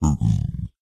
Sound / Minecraft / mob / zombiepig / zpig4.ogg